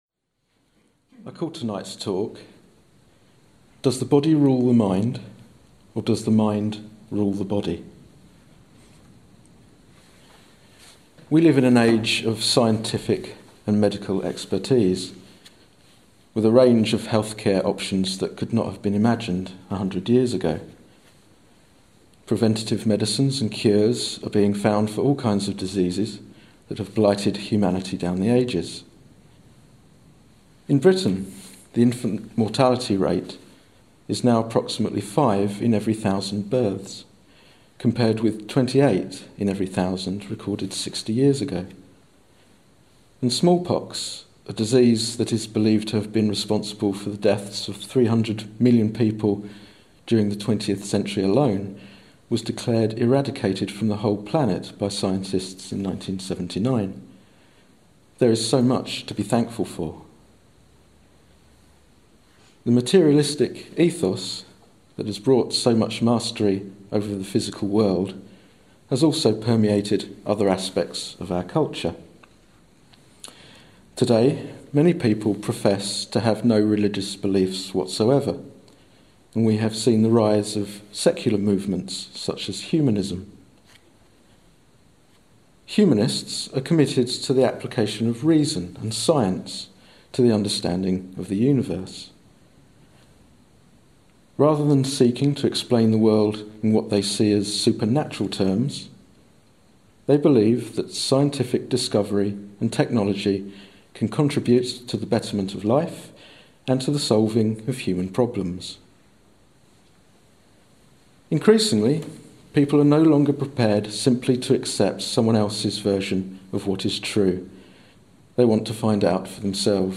This talk looks at how exploration of our own mind and body through vipassana meditation shows us exactly how life comes into being moment by moment.